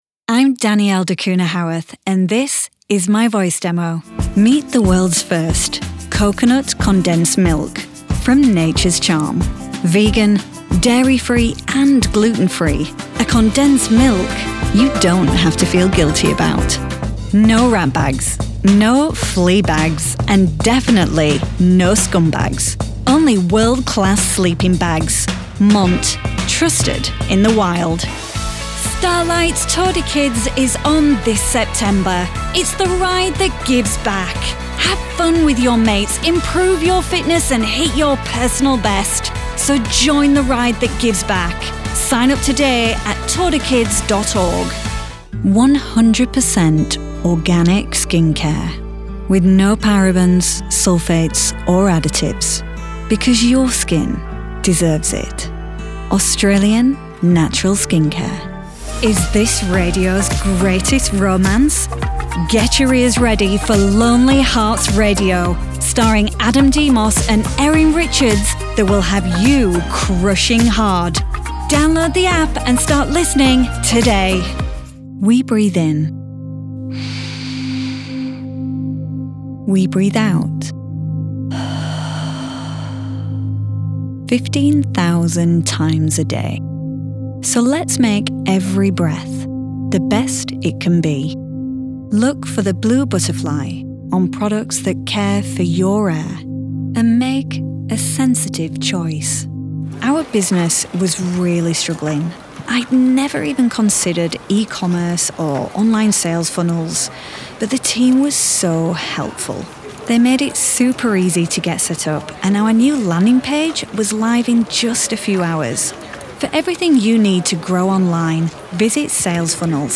British VO Pro
Yorkshire, Humber, Scottish, Lancashire, London